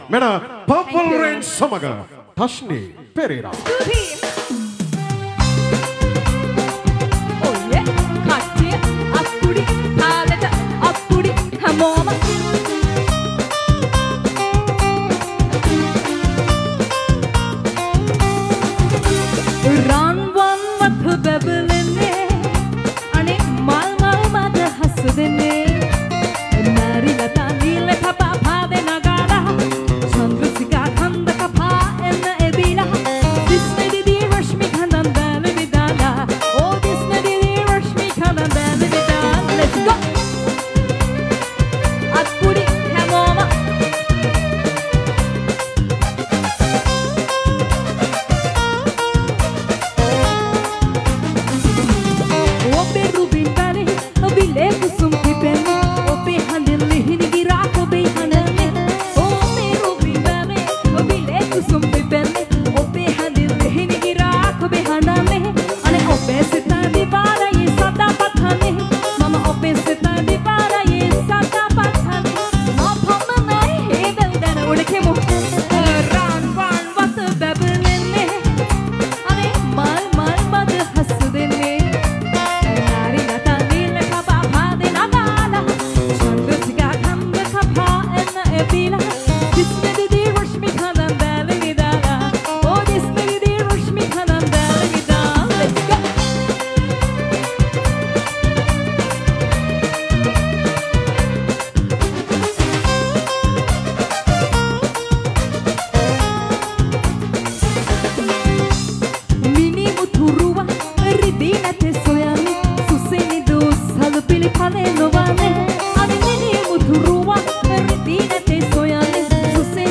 Live in Narangodapaluwa